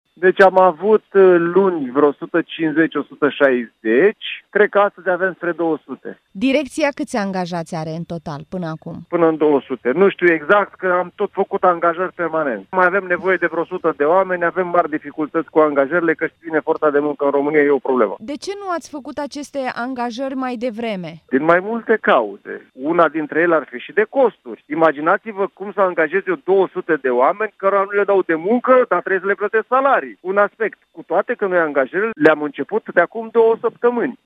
De ce, explică Primarul Robert Negoița.